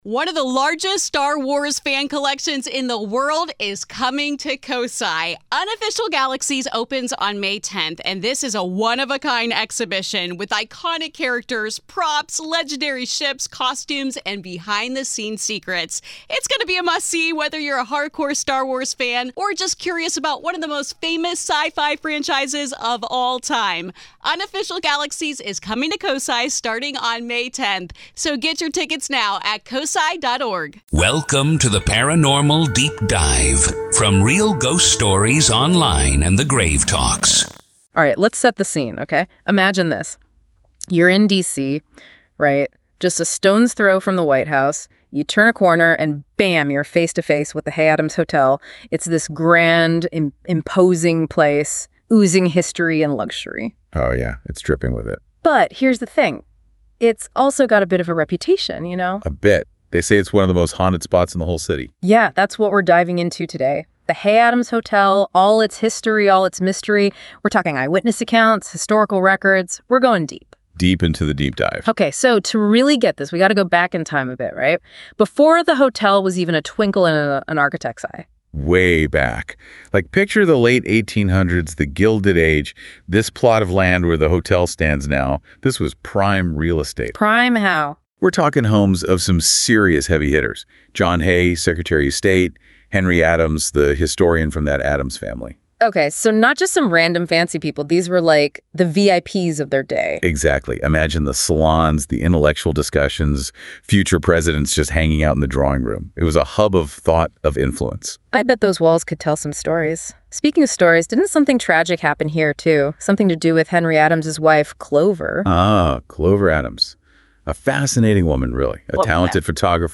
Deep Dive DISCUSSION!